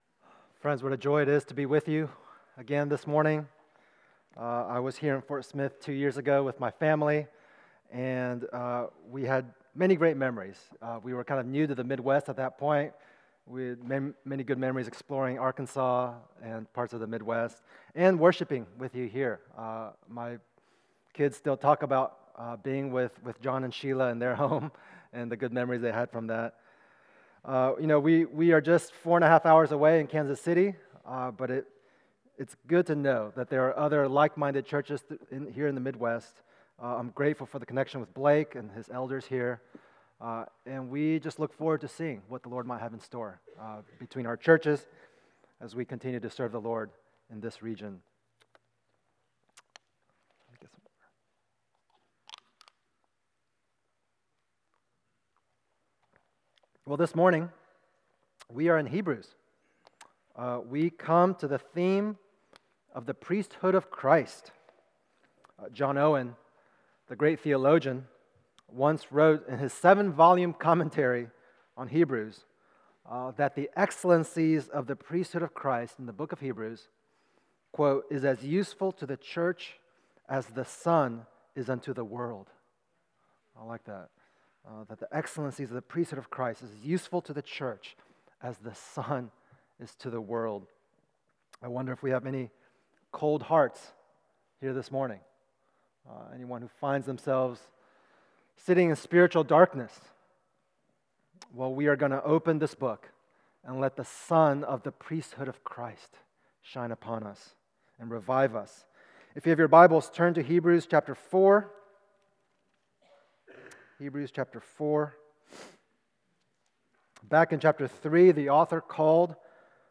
CCBC Sermons